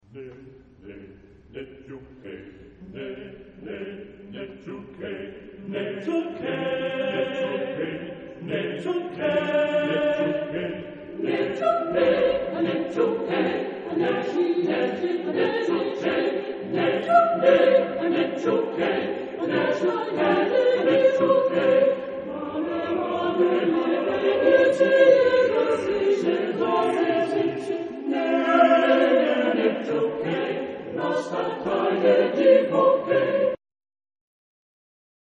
Genre-Style-Forme : Chanson ; Folklore ; Profane
Caractère de la pièce : joyeux ; dansant
Type de choeur : SATB  (4 voix mixtes )
Tonalité : sol (centré autour de)